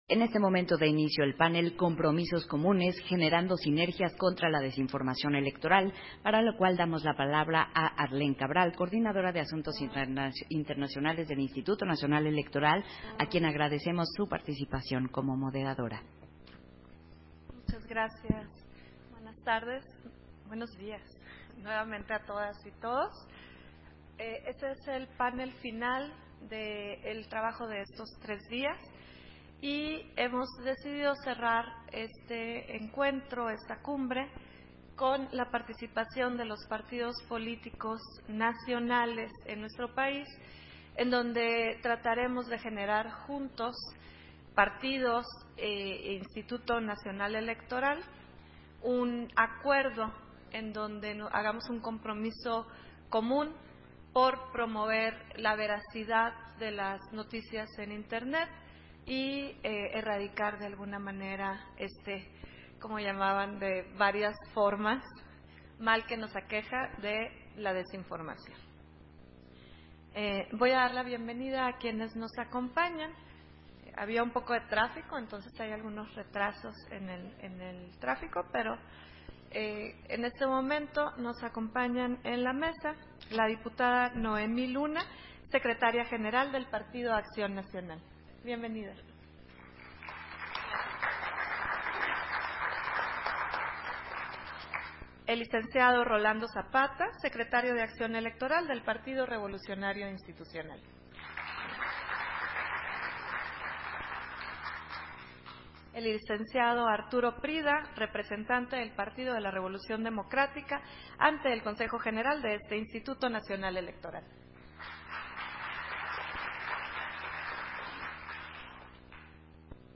061223_AUDIO__PANEL-COMPROMISOS-COMUNES
Versión estenográfica del panel, Compromisos comunes: Generando sinergias contra la desinformación electoral. En el marco de la II Cumbre de la Democracia Electoral